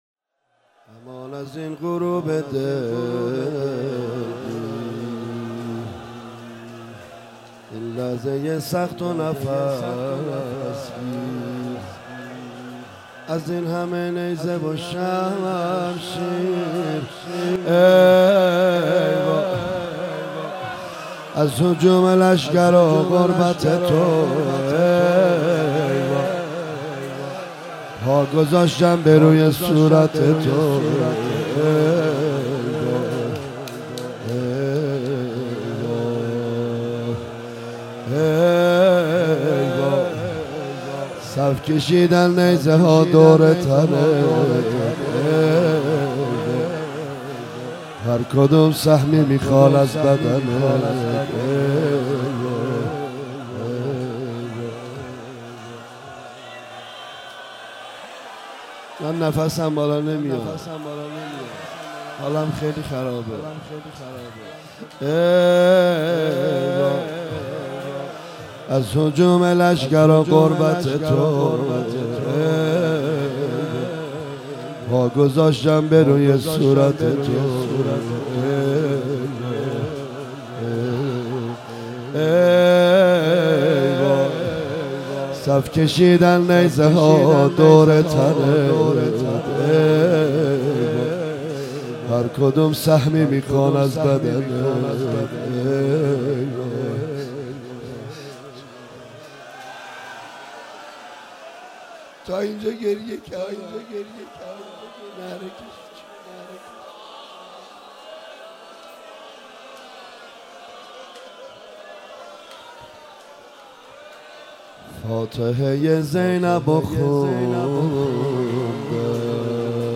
هیئت مکتب الحسین (ع)